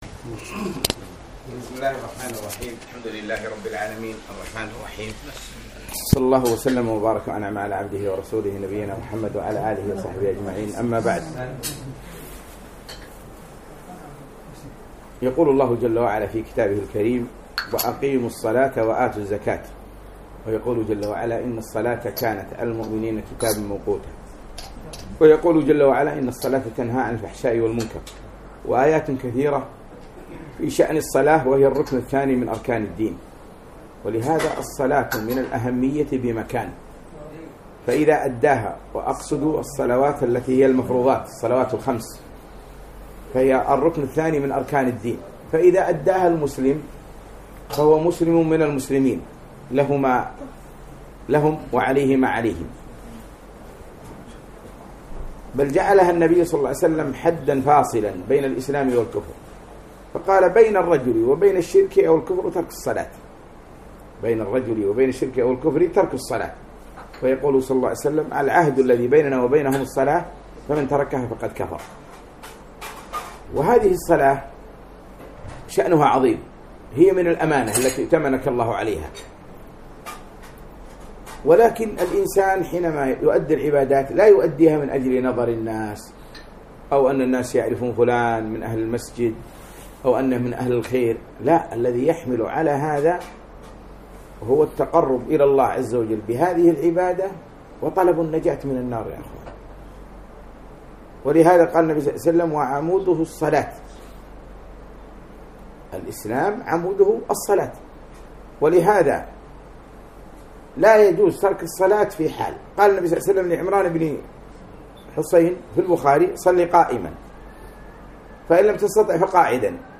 أهمية الصلاة - كلمة